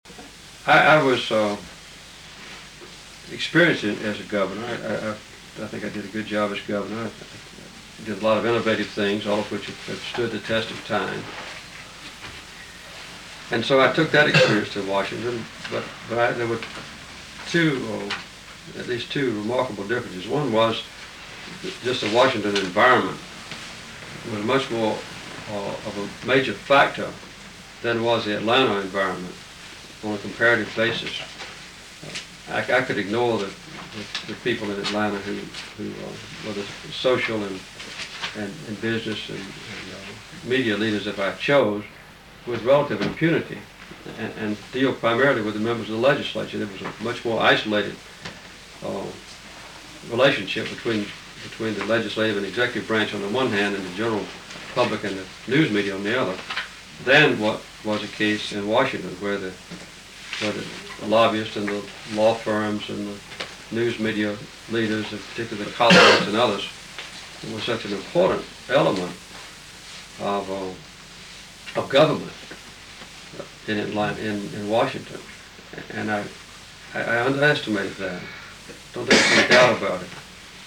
In trying to accomplish his administration’s goals, he ran up against legislative and media forces in Washington more powerful than their counterparts in Georgia. Date: November 29, 1982 Participants Jimmy Carter Associated Resources Jimmy Carter Oral History The Jimmy Carter Presidential Oral History Audio File Transcript